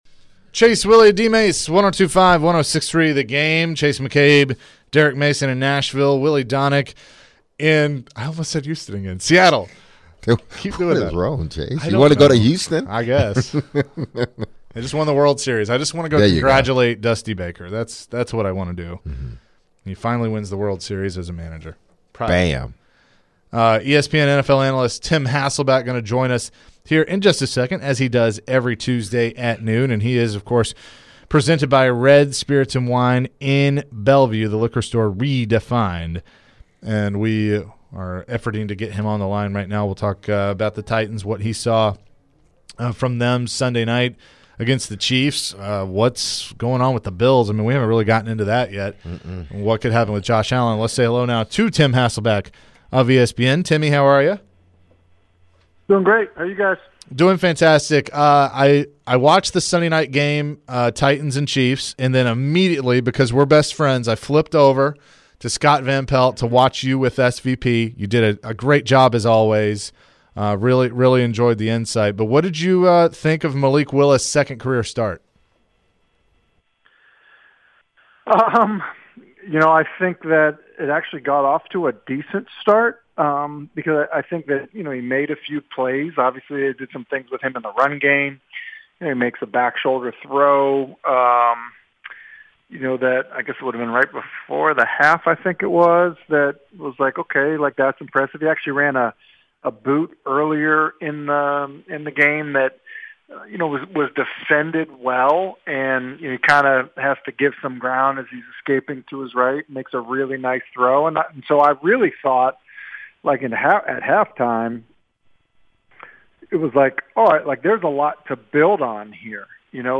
Tim Hasselbeck Full Interview (11-08-22)